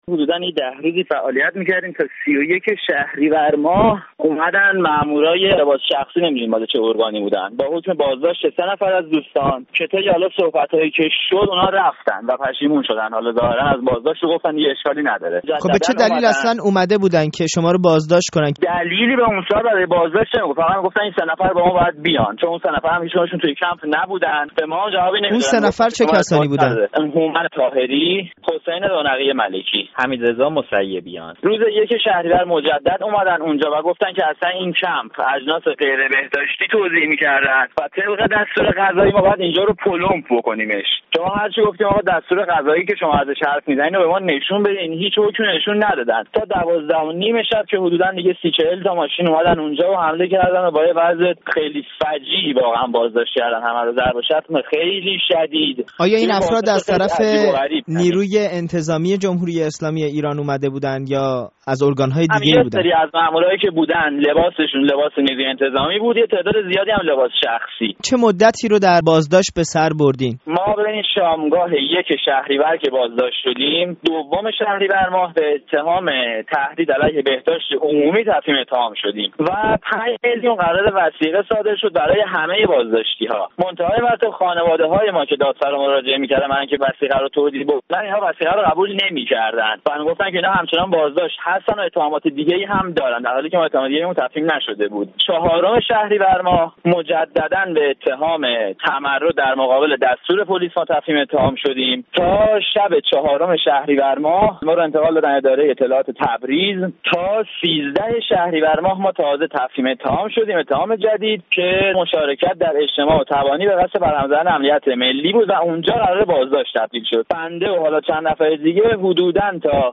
گفت‌وگو با یک امدادگر زلزله آذربایجان که سر از زندان درآورد